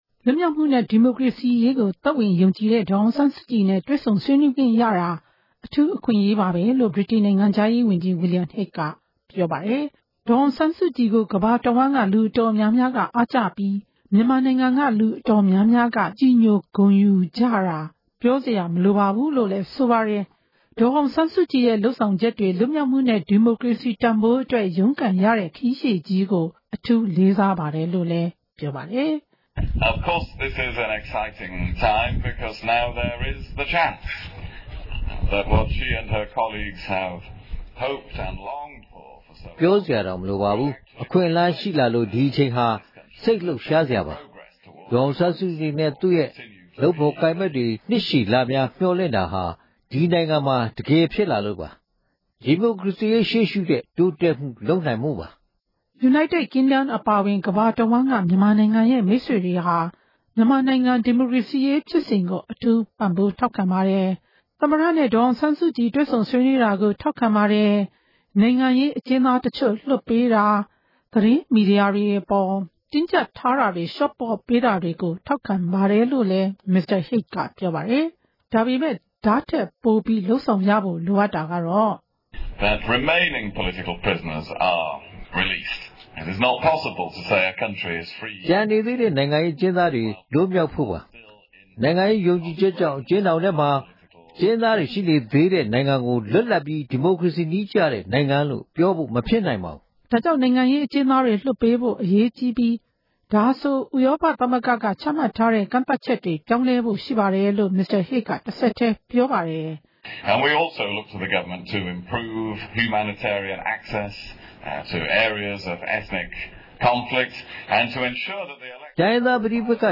မြန်မာနိုင်ငံမှာ အရှိန်အဟုန်နဲ့ ပြောင်းလဲနေတာ ထင်ရှားပေမယ့် နိုင်ငံတကာအနေနဲ့ မြန်မာ အာဏာပိုင်တွေအပေါ် ဆက်လက် ဖိအားပေးဖို့ လိုအပ်တယ်လို့ ရန်ကုန်မြို့ တက္ကသိုလ်ရိပ်သာလမ်း ဒေါ်အောင်ဆန်းစုကြည် နေအိမ်မှာ လုပ်တဲ့ သတင်းစာရှင်းလင်းပွဲမှာ Mr. Hague က ပြောကြားလိုက်တာပါ။
စုစည်းတင်ပြချက်။